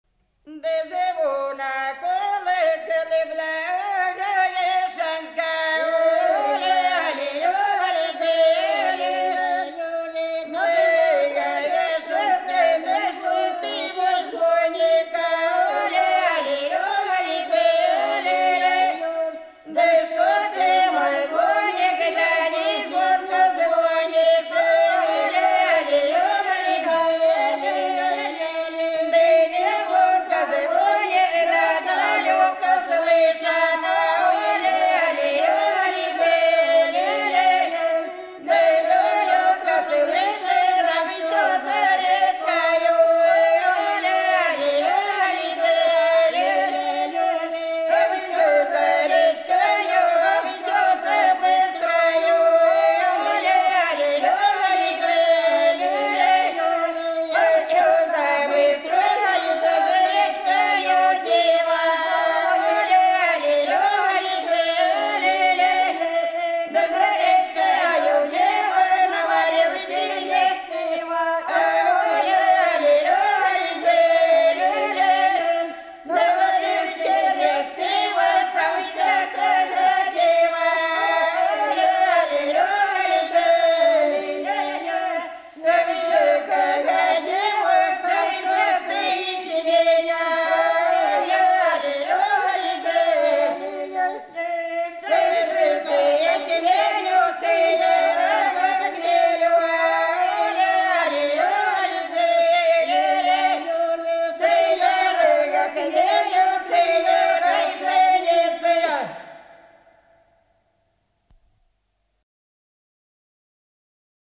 Голоса уходящего века (Курское село Илёк) Звонок колокол Благовещенский (таночная, на Благовещенье)